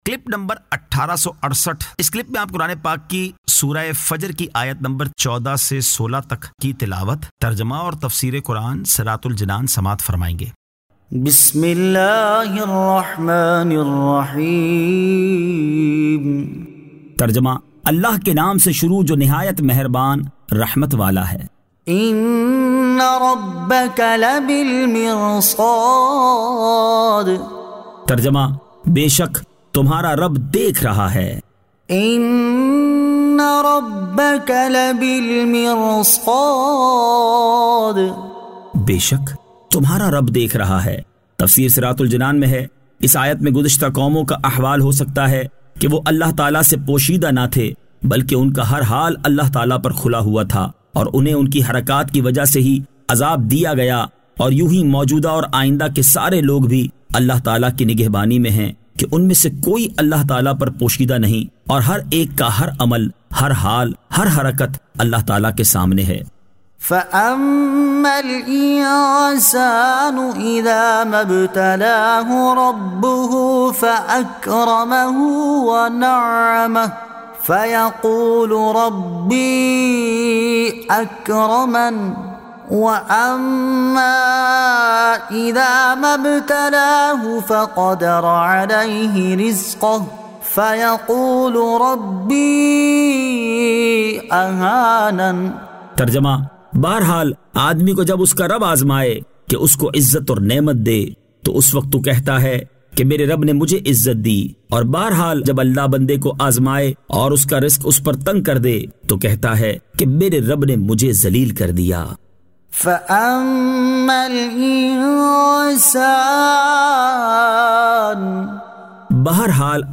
Surah Al-Fajr 14 To 16 Tilawat , Tarjama , Tafseer